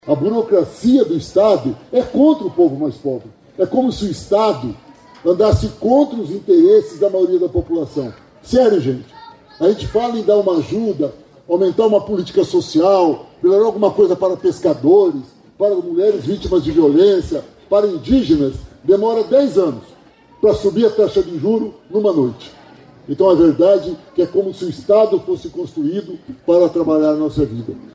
O diretor-presidente da Itaipu Binacional, Ênio Verri, criticou a burocracia em ações de regularização fundiária.